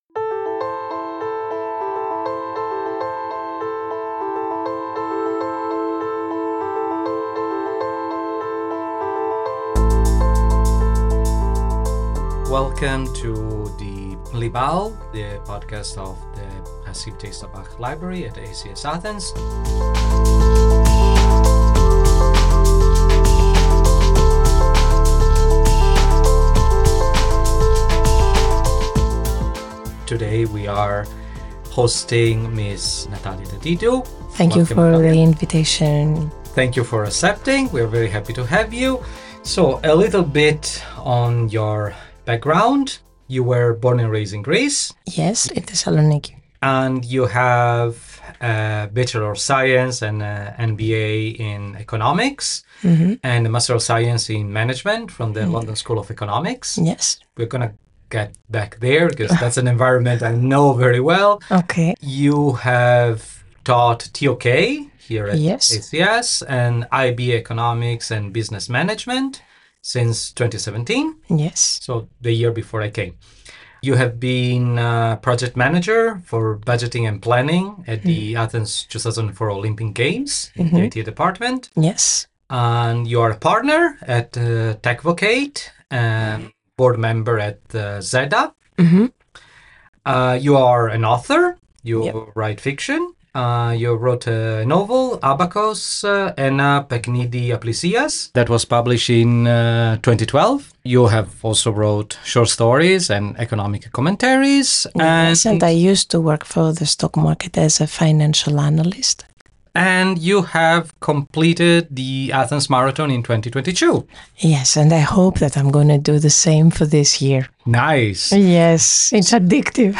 engaging conversation